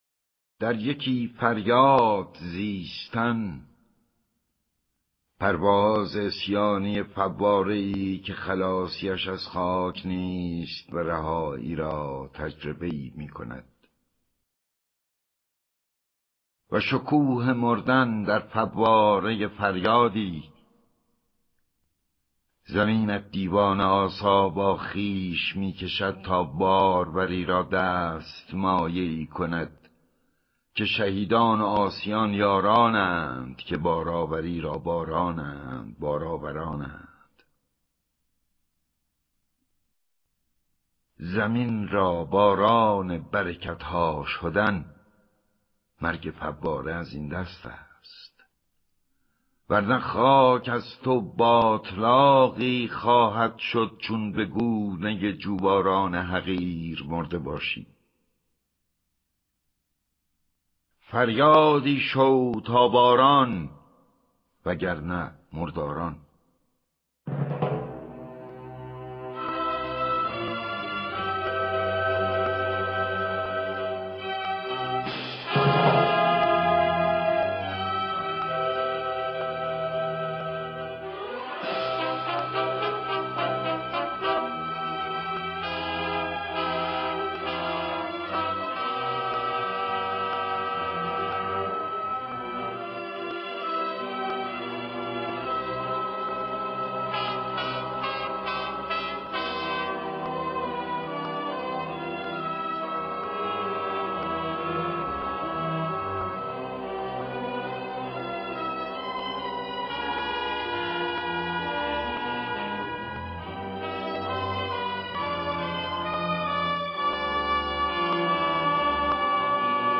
دانلود دکلمه تمثیل با صدای احمد شاملو
گوینده :   [احمد شاملو]